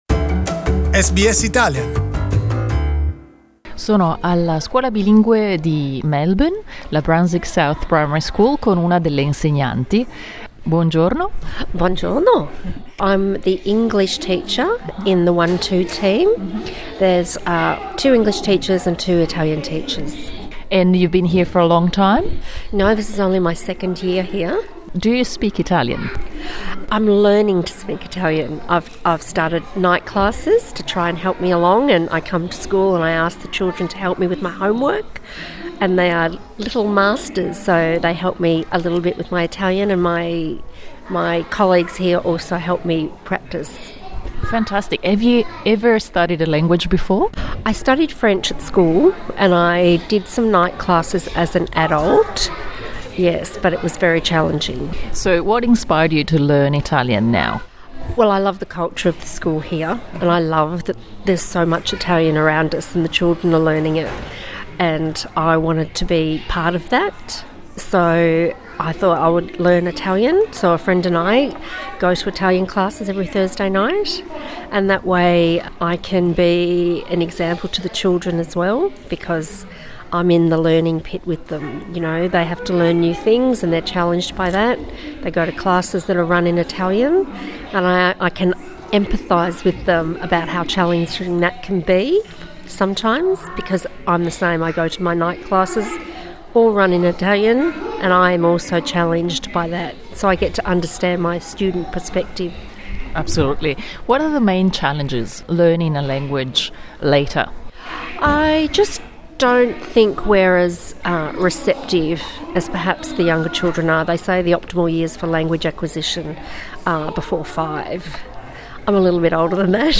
L'intervista audio è in inglese.